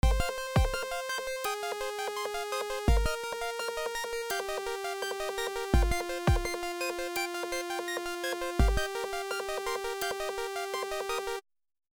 Pretty botched. For some reason the kick survived.
(This small track only uses factory sounds)